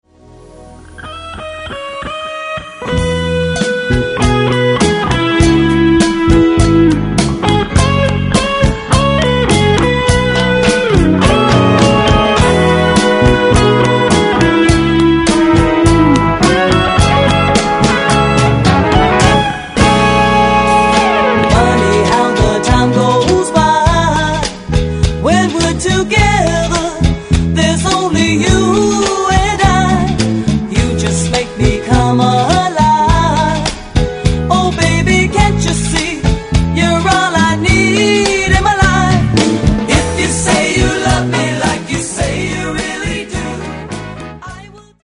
究極のチカーノ・ソウル・コンピレーション！